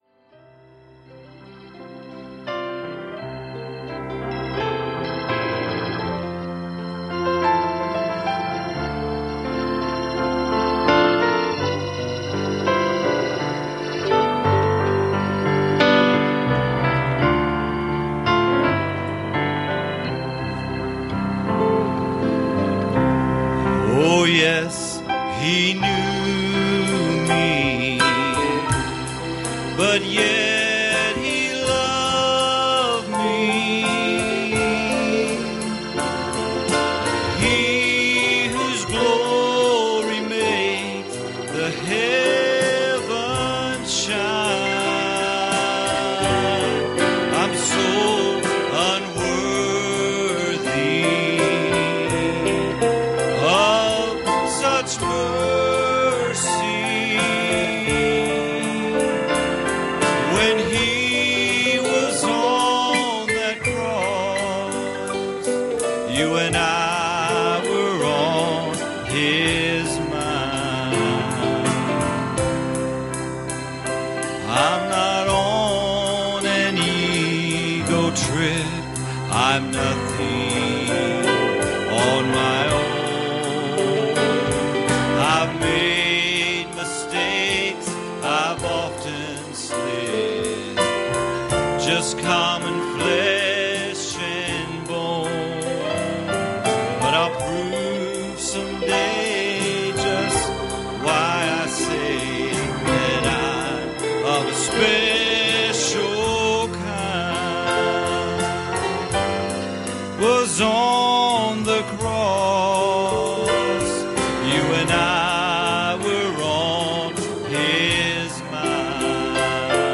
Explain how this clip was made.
Passage: Hebrews 11:7 Service Type: Sunday Morning